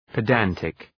Shkrimi fonetik {pı’dæntık}